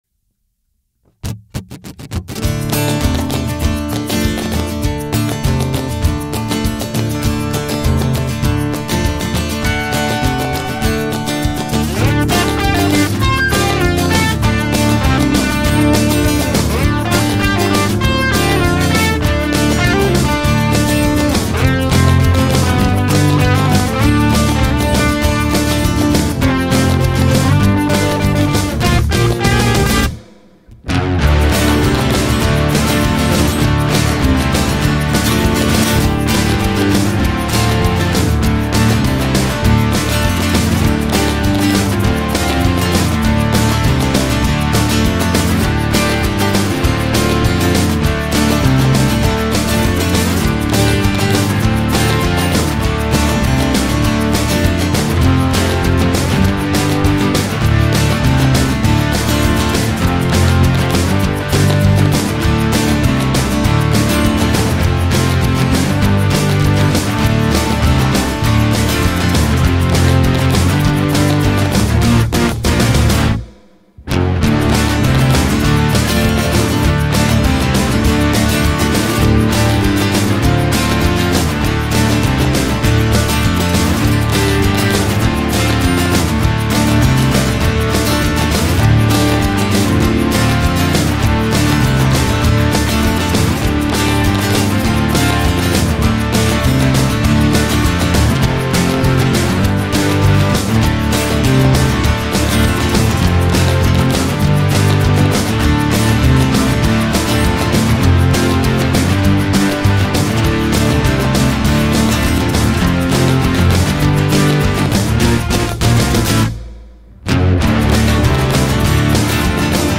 an instrumental piece